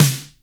Index of /90_sSampleCDs/Roland L-CD701/KIT_Drum Kits 3/KIT_West Coast
SNR LA FAT01.wav